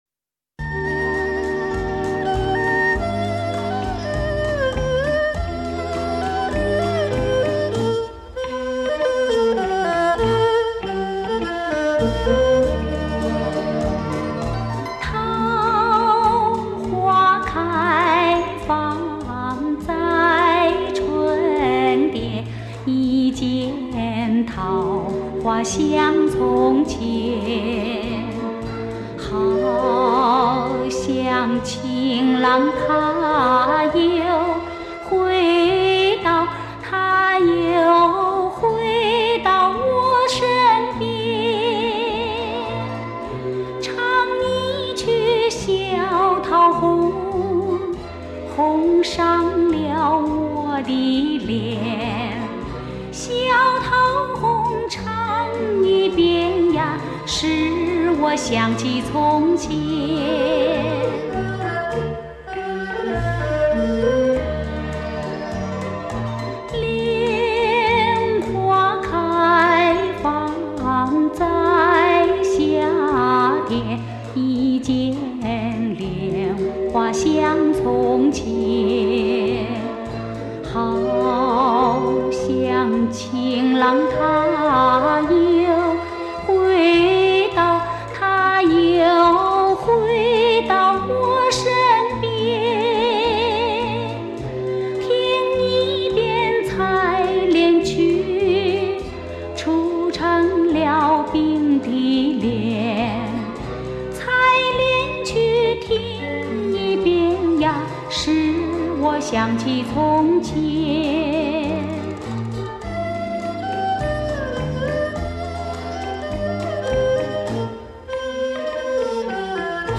本系列CD收集了台湾多年来脍炙人口的经典歌曲，用最值得珍藏之原唱者母带，以当代最先进之录制器材重新录制。